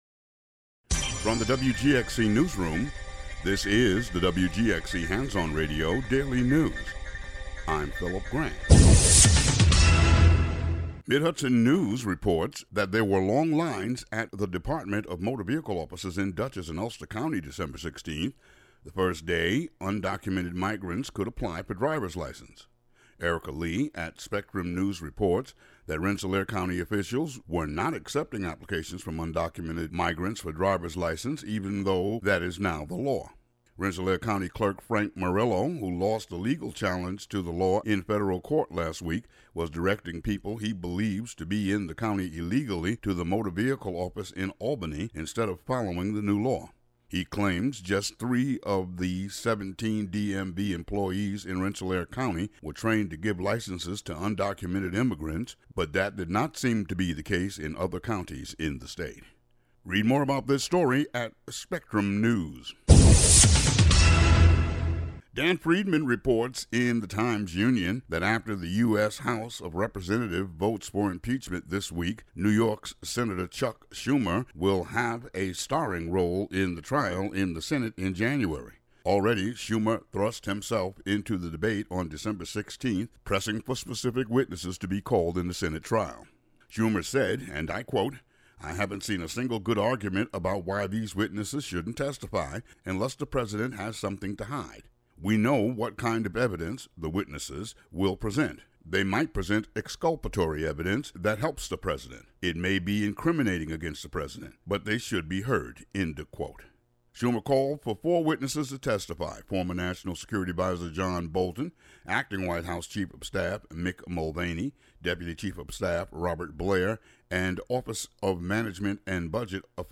The audio version of the local news for Tue., Dec. 17.